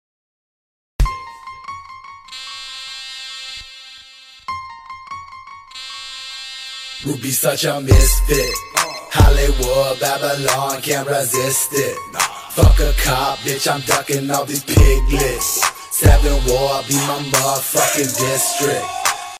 Ranger regiment training heavy weapons#military sound effects free download